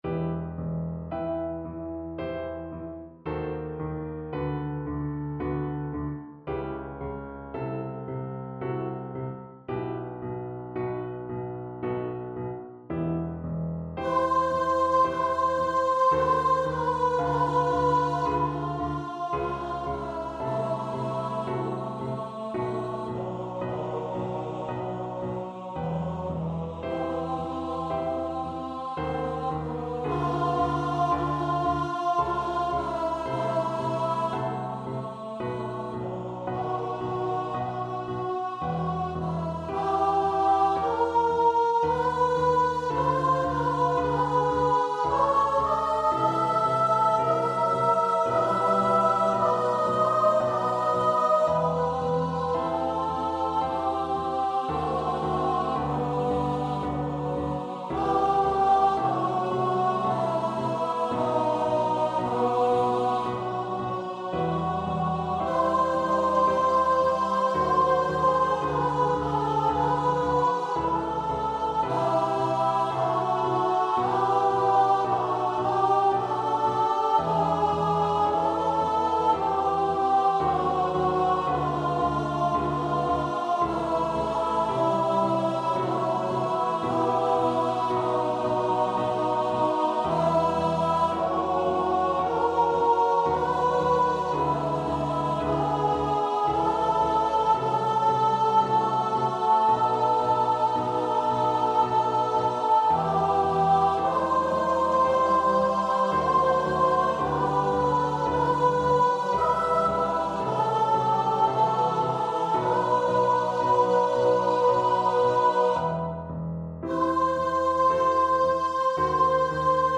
Free Sheet music for Choir (SATB)
Classical (View more Classical Choir Music)